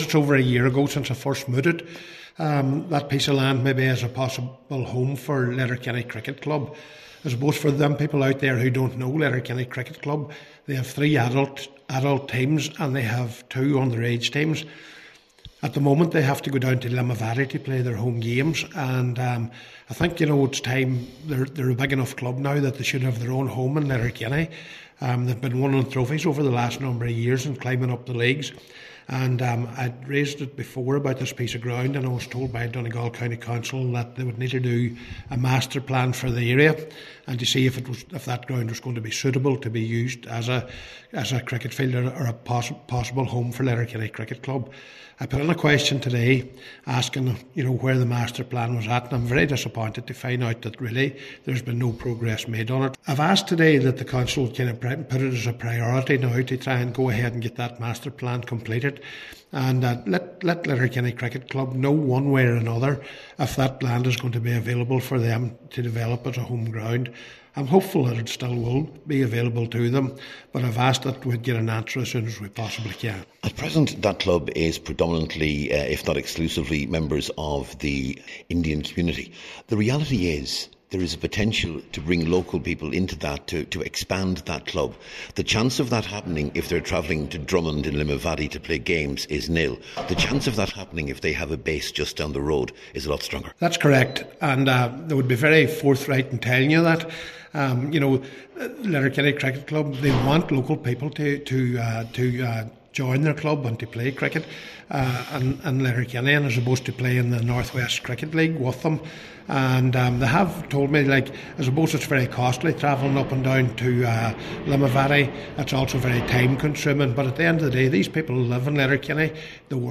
At a recent MD meeting, Cllr McBride called for the plan to be progressed as a matter of urgency: